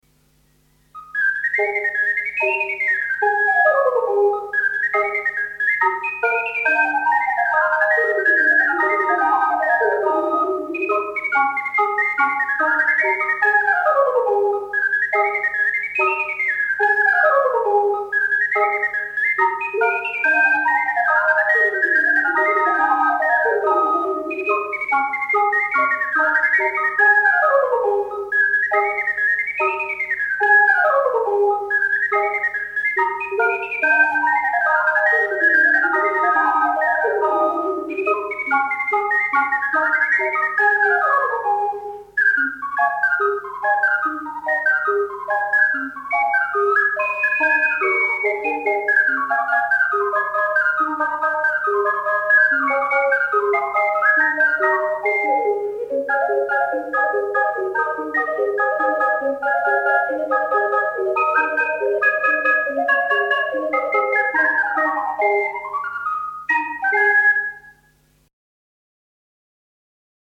Balli popolari emiliani in .mp3
in incisione multipla con 5 ocarine